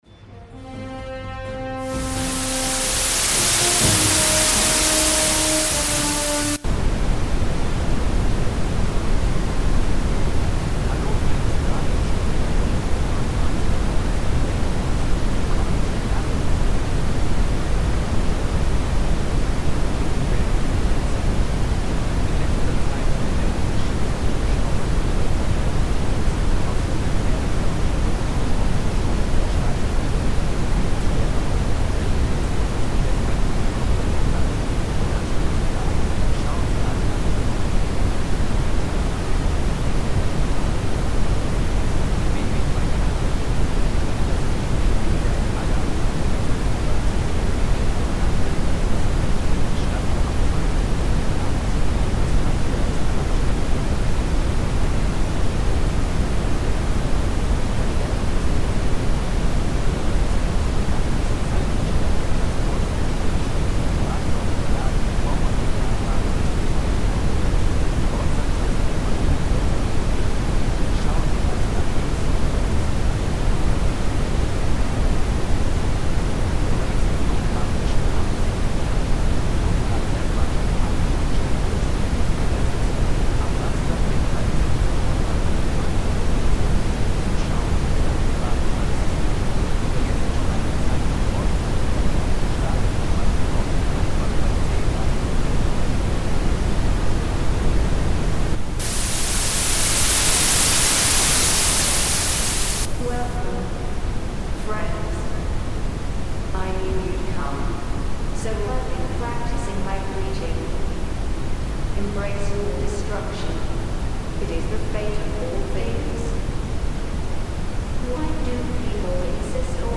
[folgende Version wird aufgrund von atmosphärischen Beeinflussungen in Chernarus empfangen]